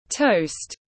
Bánh mì nướng tiếng anh gọi là toast, phiên âm tiếng anh đọc là /təʊst/
Toast /təʊst/